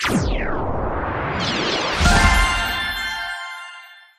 Appear_Scatter_Win_Sound.mp3